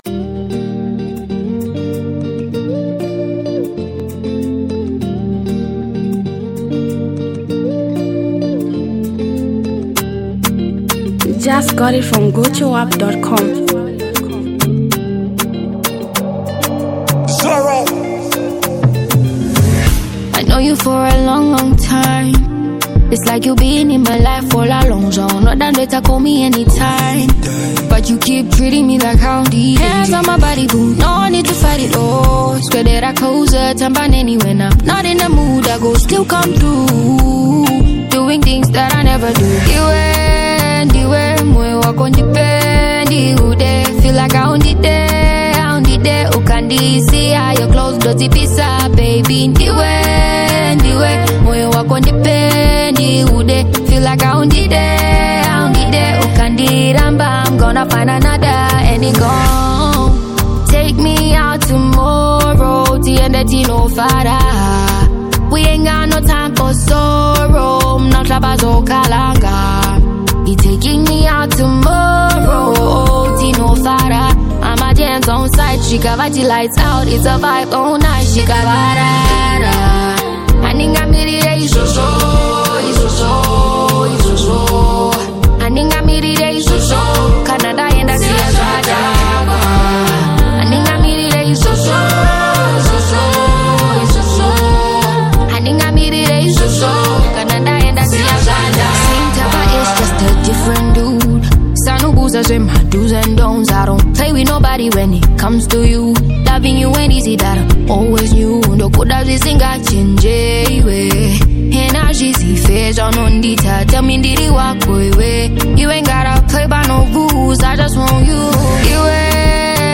Zimbabwean renowned talented singer